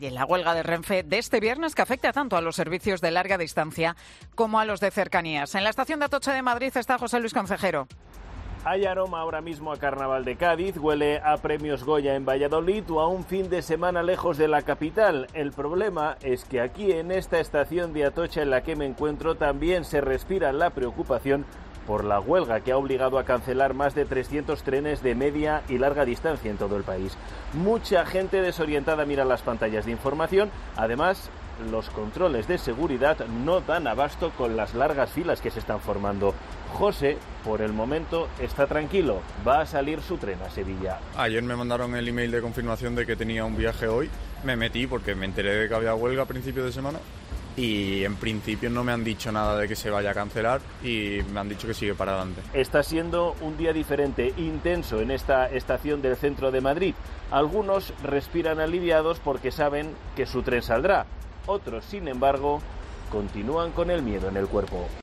COPE testigo: Incertidumbre entre los viajeros en la estación de Atocha ante la huelga de Renfe